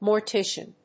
音節mor・ti・cian 発音記号・読み方
/mɔɚtíʃən(米国英語), mɔːtíʃən(英国英語)/